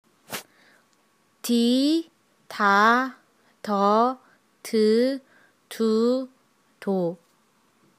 Click the letters on the left of the table to hear how a specific consonant is pronounced with each vowel.